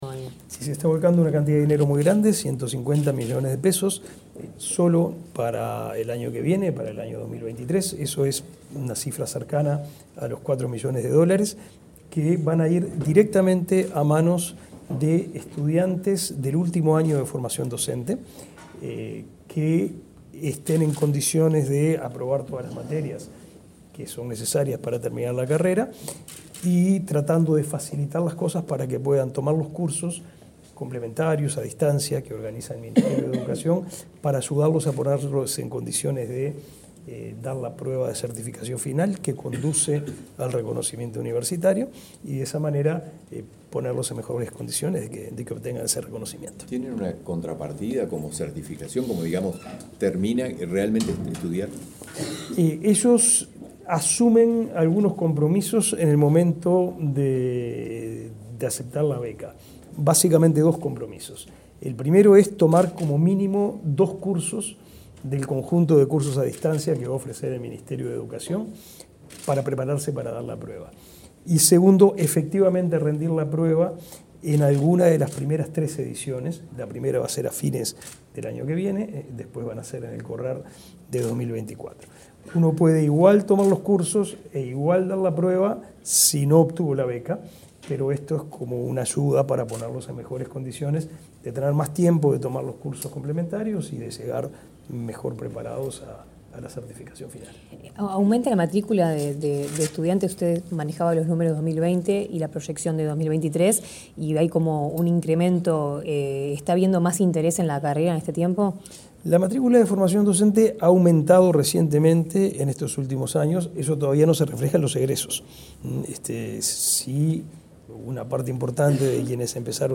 Declaraciones del ministro de Educación y Cultura, Pablo da Silveira
Declaraciones del ministro de Educación y Cultura, Pablo da Silveira 18/10/2022 Compartir Facebook X Copiar enlace WhatsApp LinkedIn El ministro de Educación y Cultura, Pablo da Silveira, informó sobre becas para estudiantes de Formación Docente para 2023. Luego dialogó con la prensa.